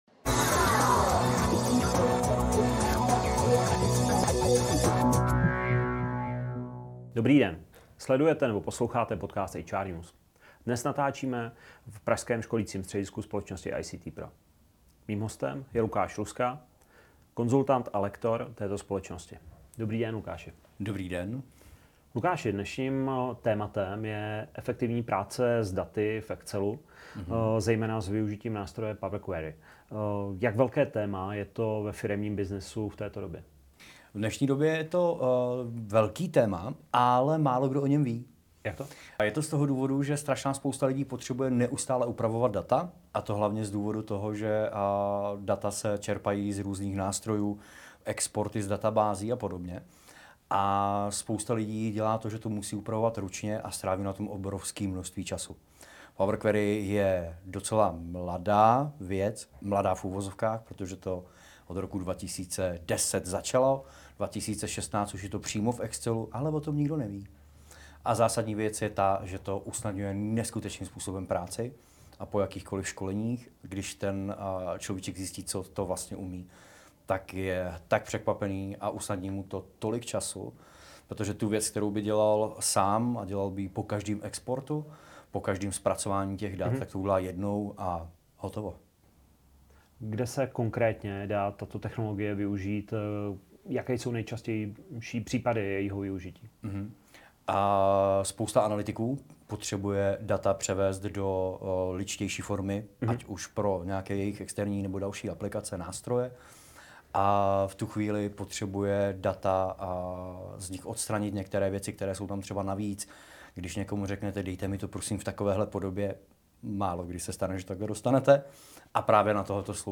Rozhovor se věnuje i nástupu umělé inteligence.